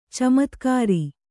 ♪ camatkāri